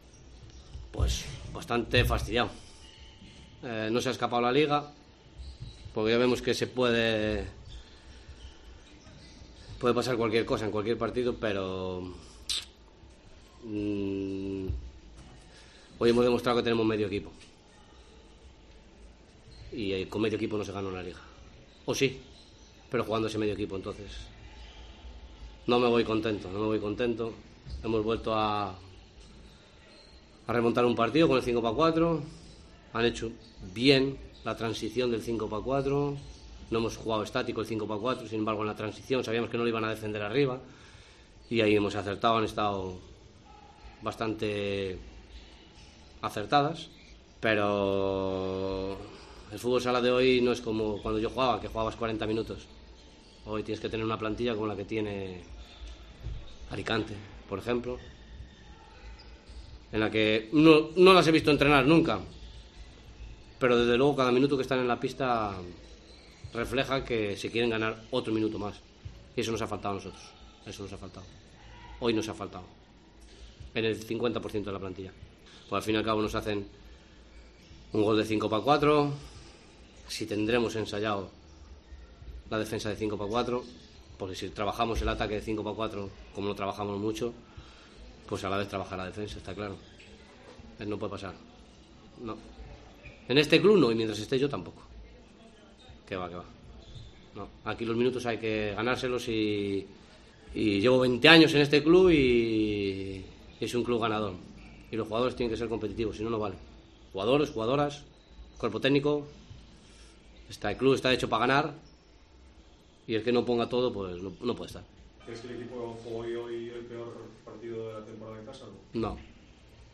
Rueda de Prensa después del Burela-Alicante